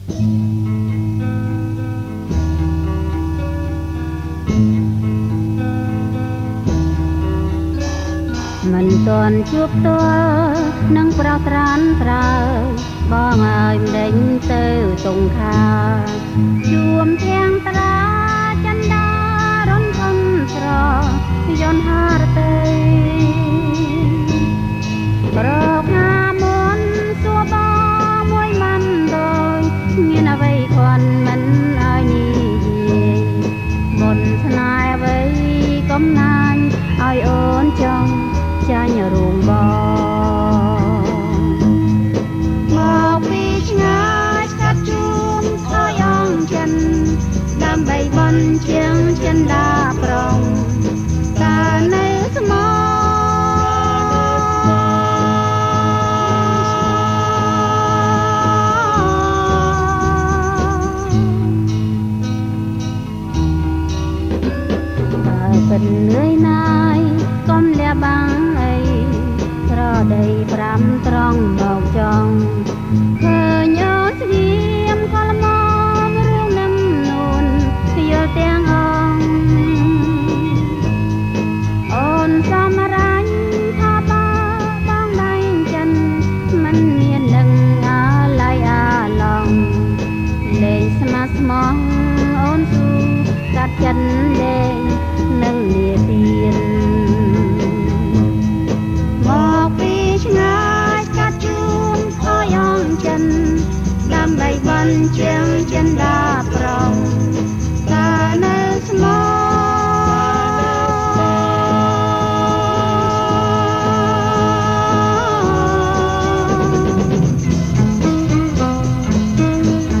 • ប្រគំជាចង្វាក់ Jerk